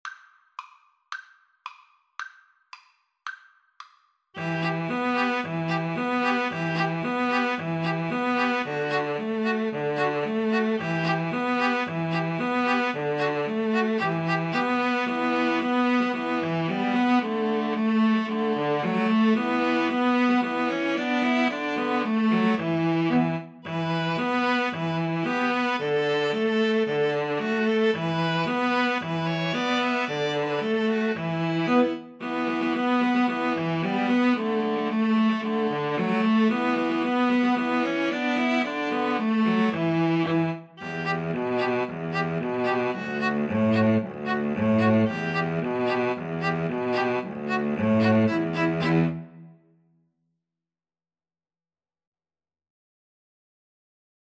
E minor (Sounding Pitch) (View more E minor Music for String trio )
Allegro moderato = c. 112 (View more music marked Allegro)
2/4 (View more 2/4 Music)
String trio  (View more Easy String trio Music)